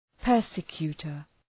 Προφορά
{‘pɜ:rsə,kju:tər}